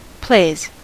Ääntäminen
Ääntäminen US : IPA : [pleɪz] Haettu sana löytyi näillä lähdekielillä: englanti Käännöksiä ei löytynyt valitulle kohdekielelle.